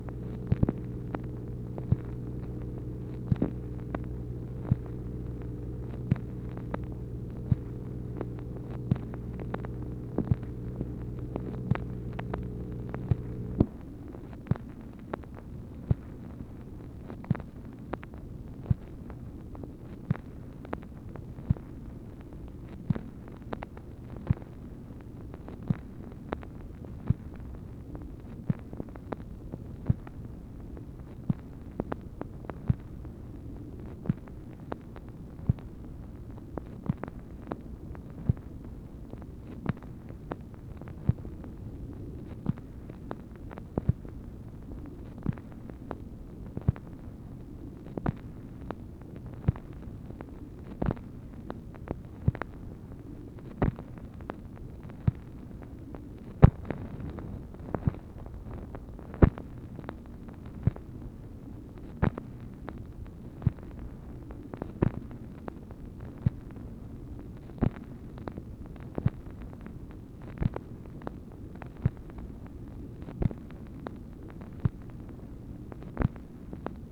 MACHINE NOISE, January 25, 1964
Secret White House Tapes | Lyndon B. Johnson Presidency